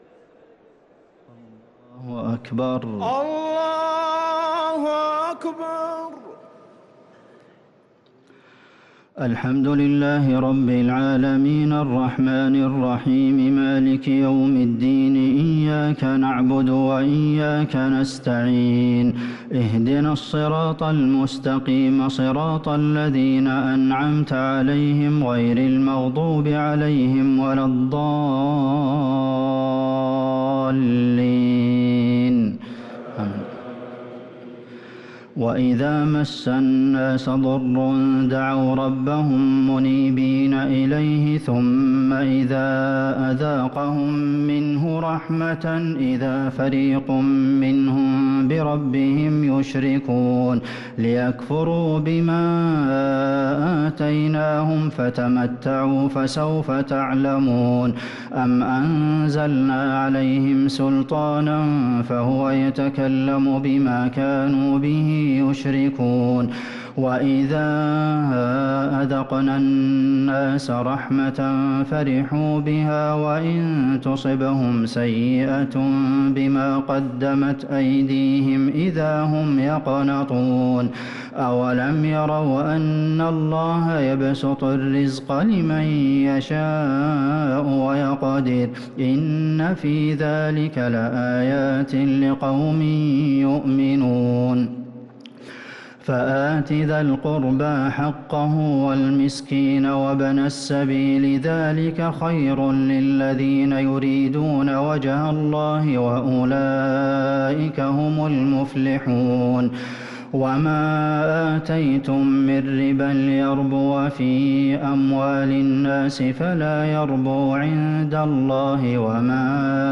صلاة التراويح ليلة 24 رمضان 1444 للقارئ عبدالمحسن القاسم - التسليمتان الأخيرتان صلاة التراويح